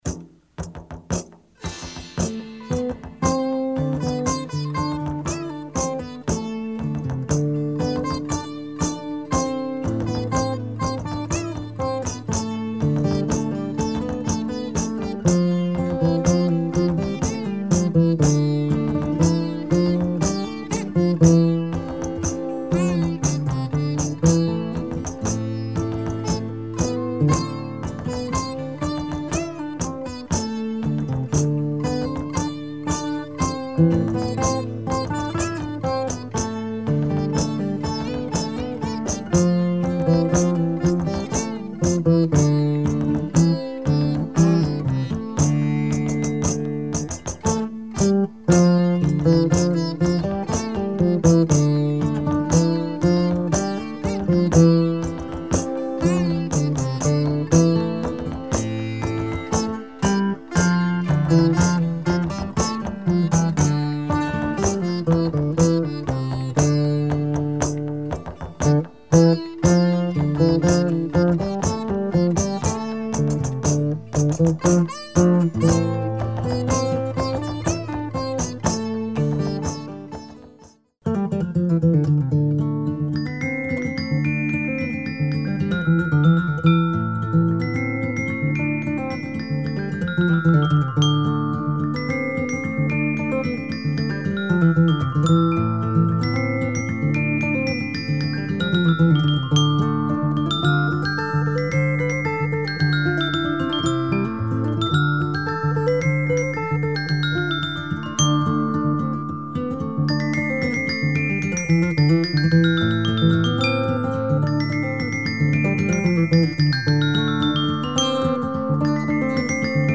1970's folk with a medieval approach.